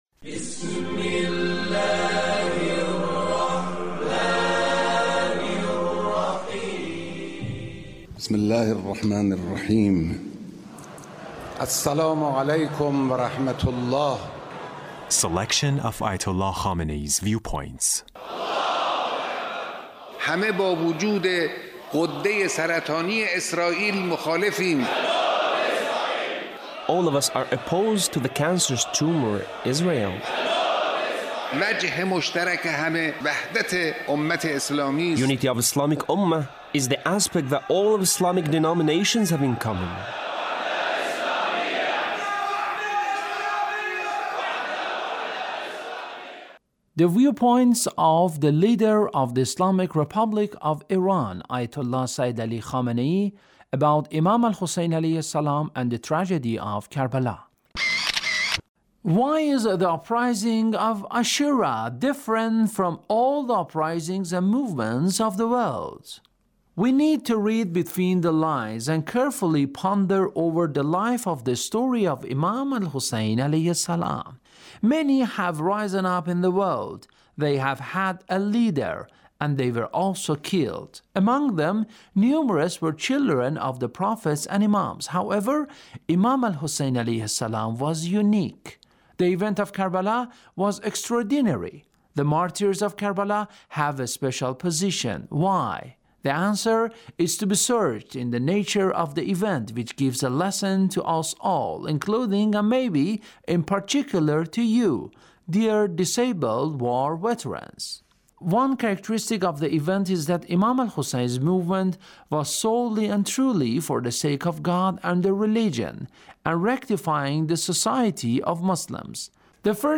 Leader's Speech (1784)